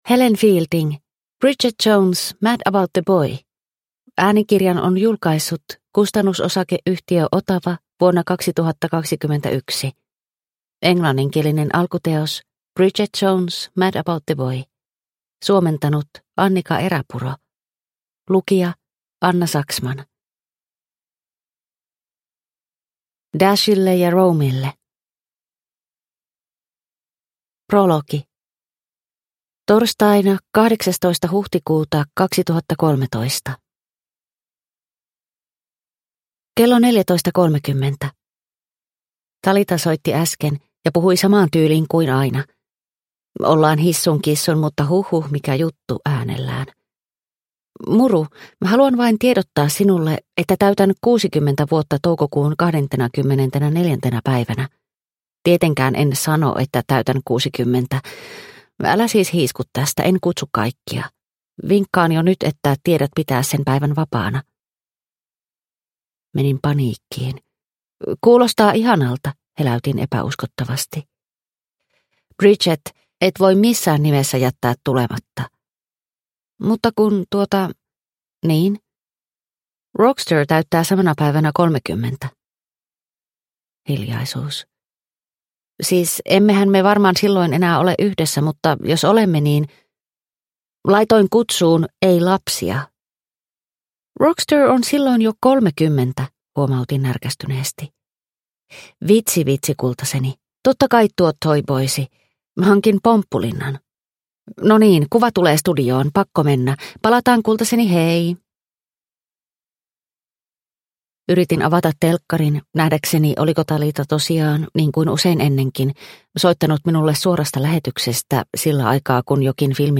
Bridget Jones - Mad about the boy – Ljudbok – Laddas ner